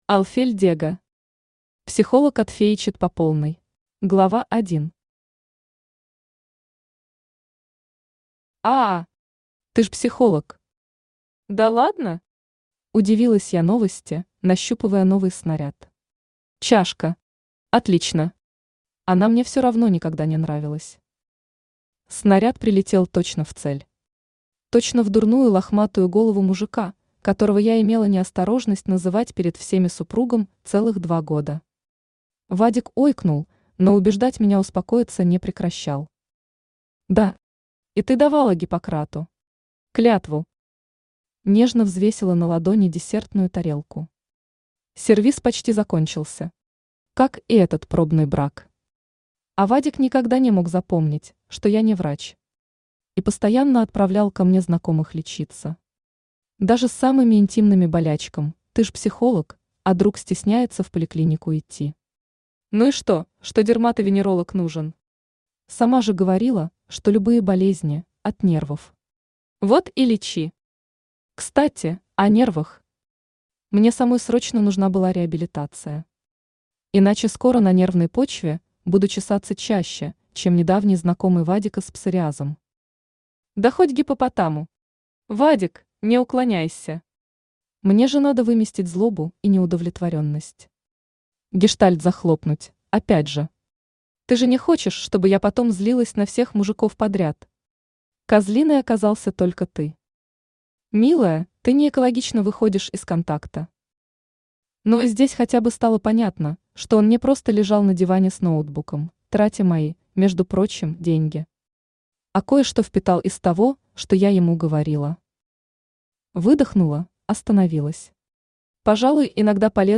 Аудиокнига Психолог отФЕЯчит по полной | Библиотека аудиокниг
Aудиокнига Психолог отФЕЯчит по полной Автор Олфель Дега Читает аудиокнигу Авточтец ЛитРес.